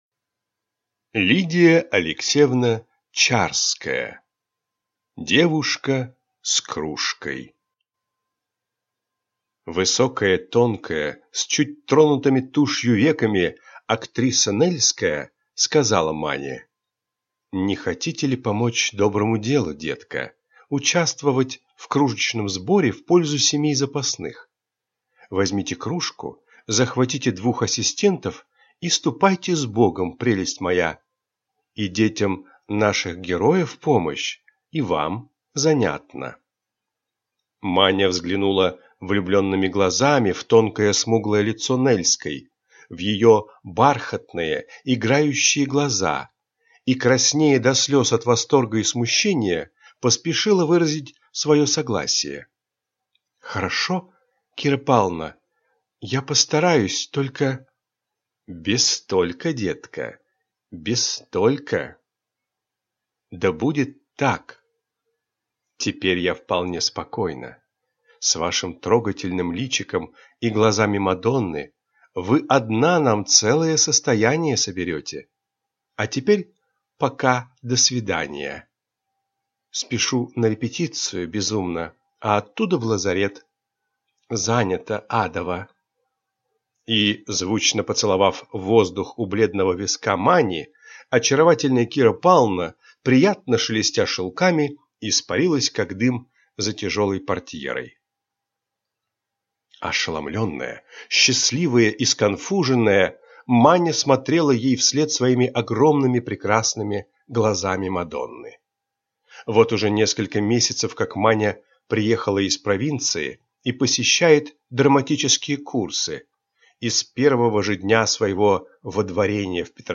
Аудиокнига Девушка с кружкой | Библиотека аудиокниг